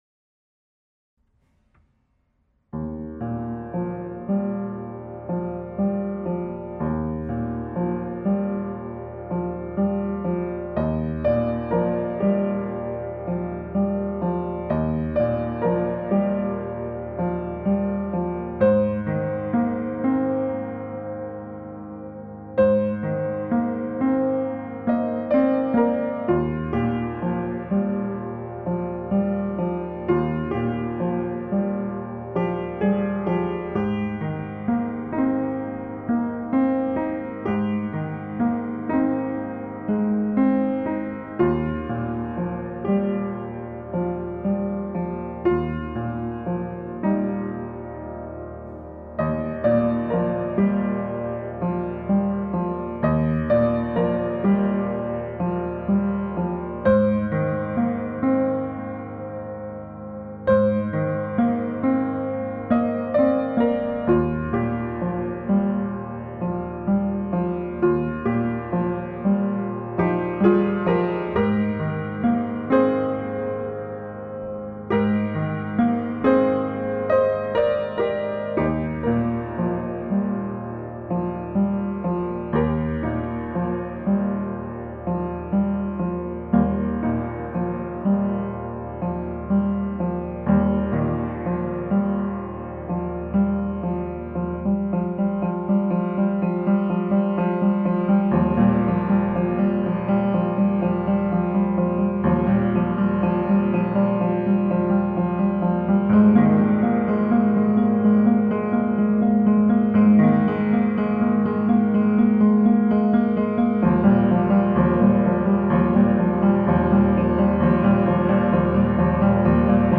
Original works for the piano